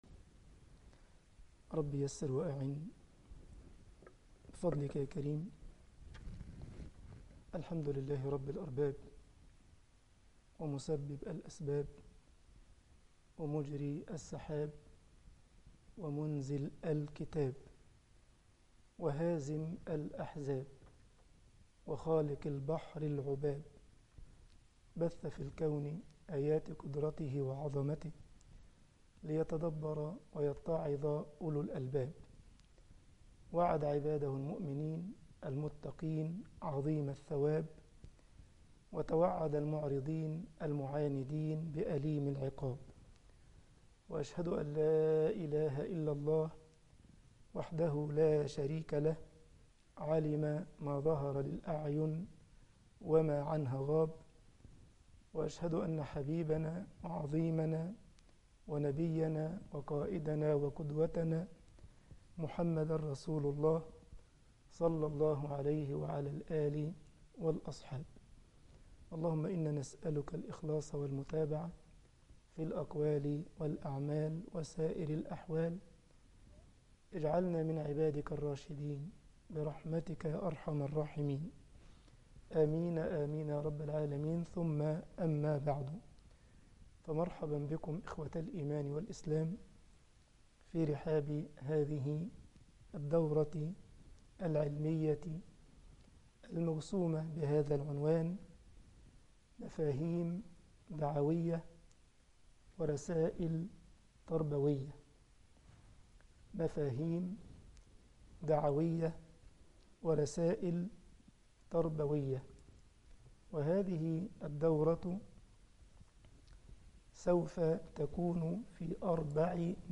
دورة علمية بعنوان مفاهيم دعوية ورسائل تربوية المحاضرة 1 طباعة البريد الإلكتروني التفاصيل كتب بواسطة